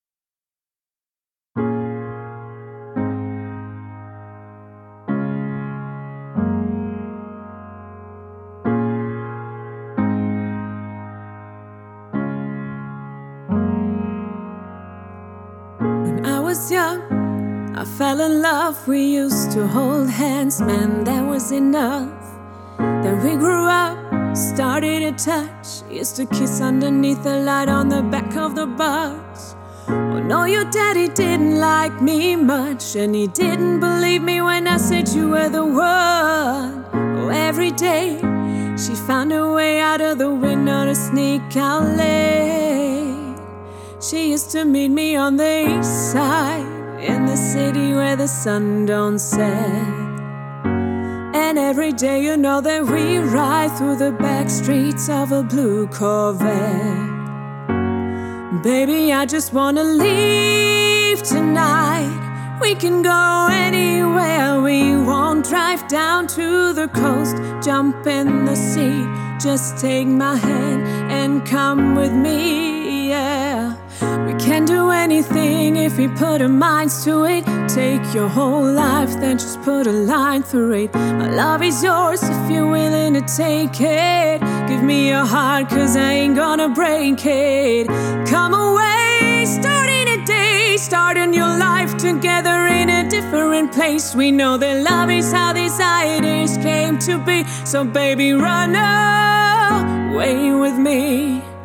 How do I get such a full and warm sound with the U4 as with this upright piano in the link?
.. on my headphones it sounds very good! the voice also!
This is a demo from a recording forum. The piano is the Modern Upright from Addictive Keys.
Eastside_test_2_Upright.mp3